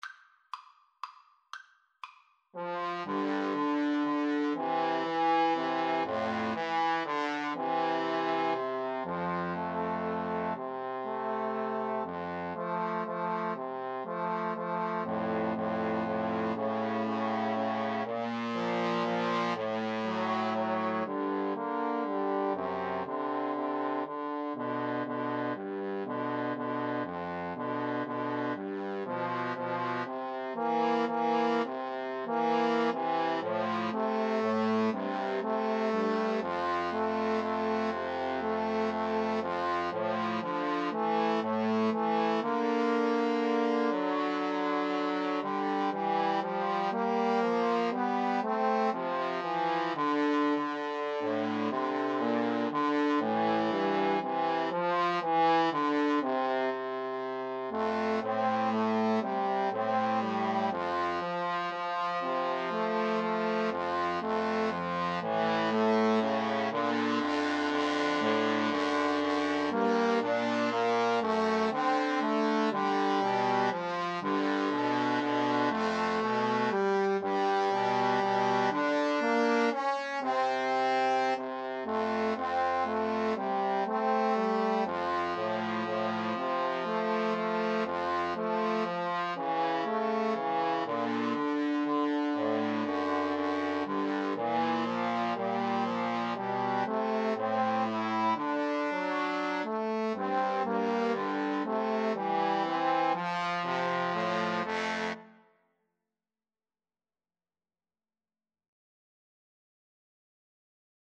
Trombone 1Trombone 2Trombone 3
= 120 Tempo di Valse = c. 120
3/4 (View more 3/4 Music)